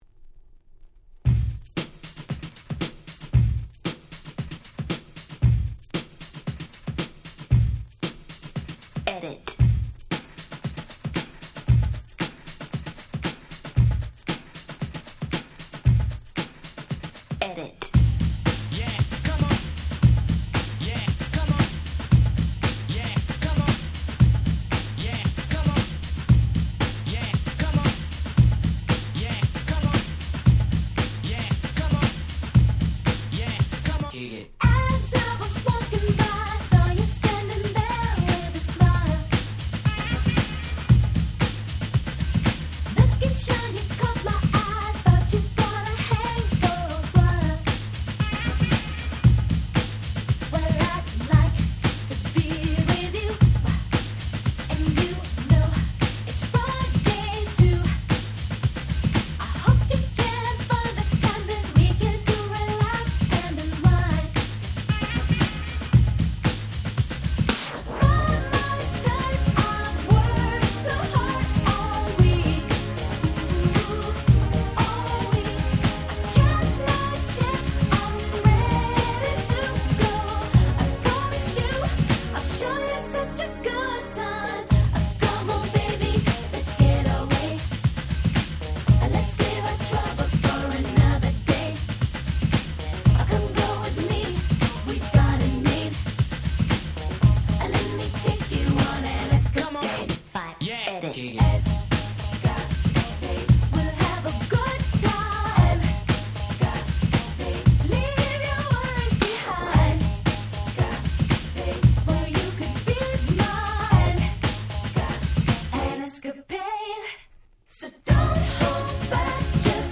89' Super Hit R&B/New Jack Swing !!
まだまだヤングだった彼女の弾けるVocalが堪りません！
このRemixがめちゃくちゃ良くて、オリジナルの2倍くらいフロア向けなんです！